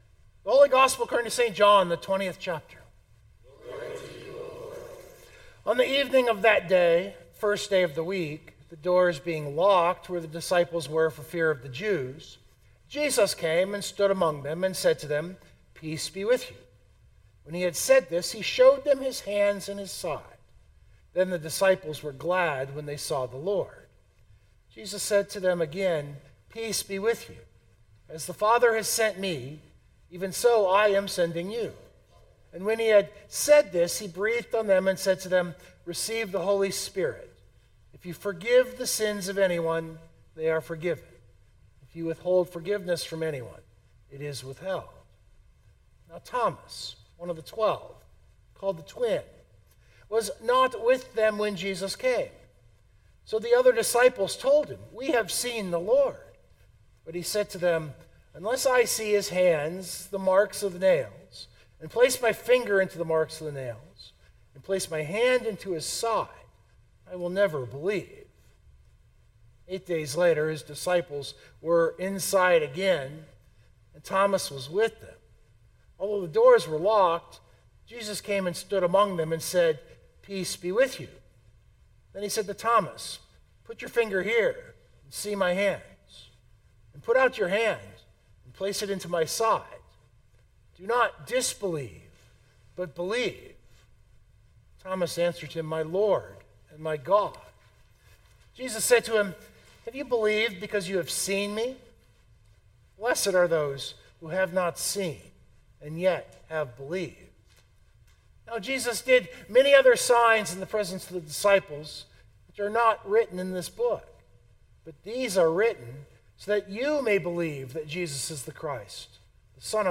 It was Palm Sunday or these days also the Sunday of the Passion.